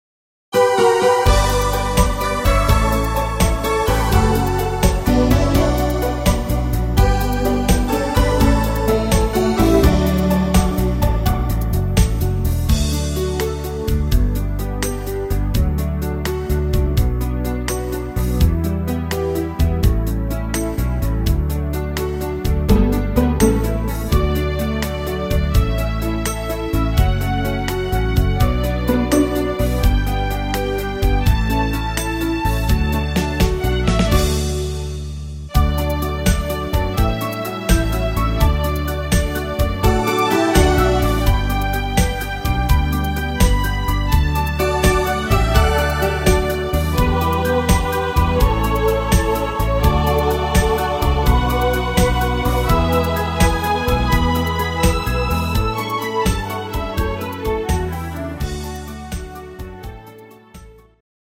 Rhythmus  Slowrock
Art  Deutsch, Mega Hits, Volkstümlicher Schlager